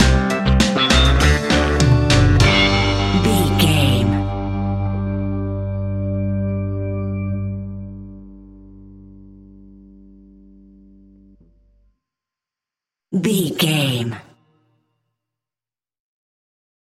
Uplifting
Aeolian/Minor
instrumentals
laid back
off beat
drums
skank guitar
hammond organ
percussion
horns